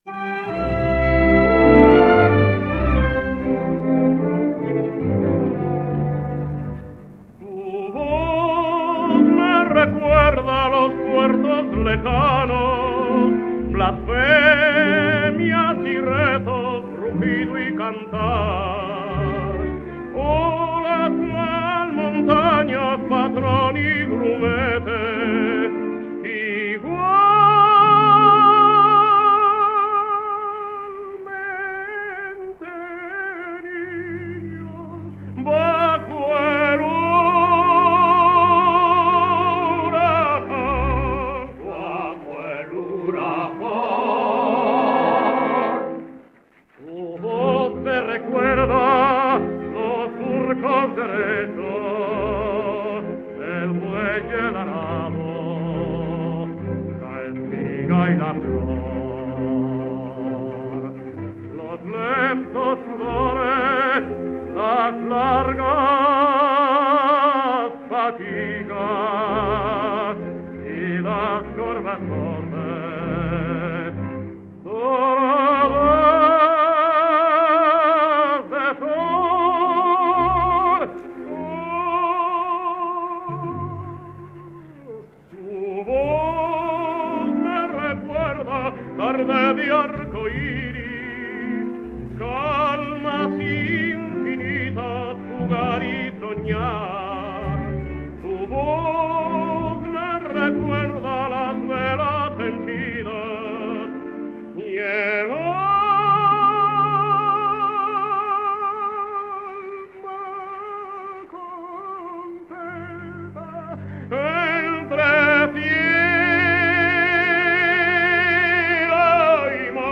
Zarzuela en tres actos
78 rpm